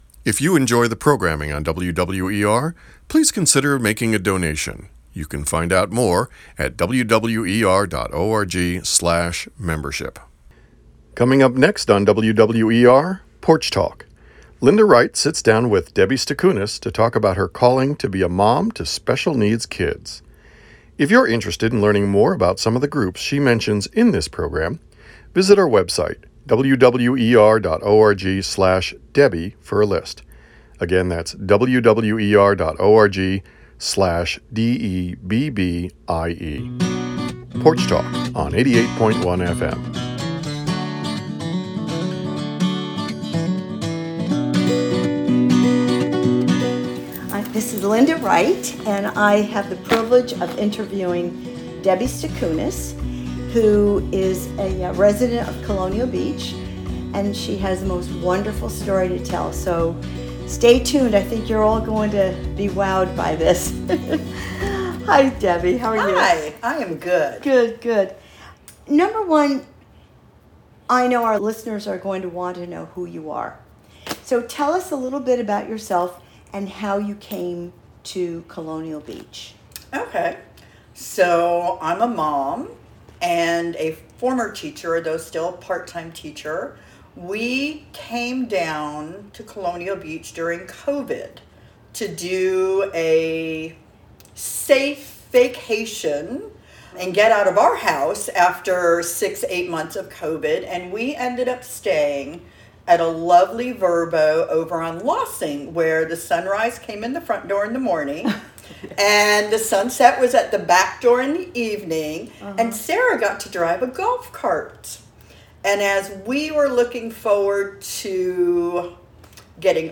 During the interview they mention several websites, here are some links.